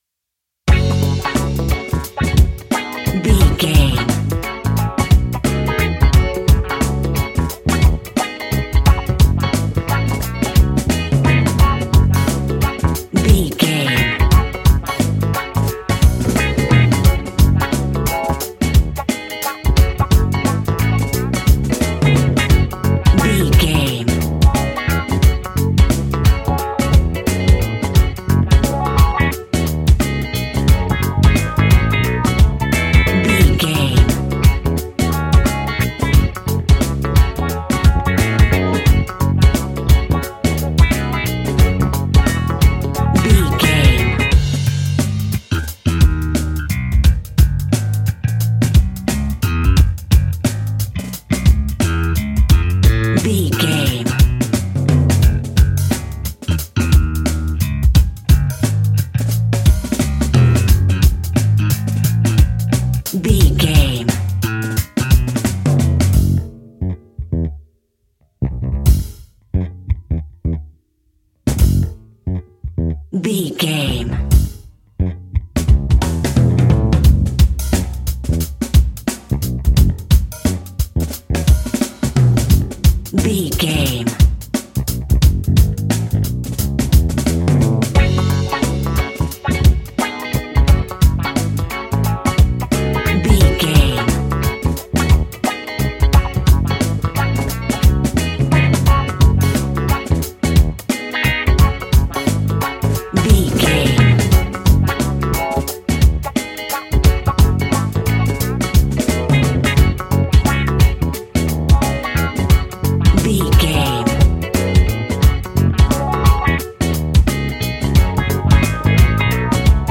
Aeolian/Minor
D♭
groovy
lively
electric guitar
electric organ
drums
bass guitar
saxophone
percussion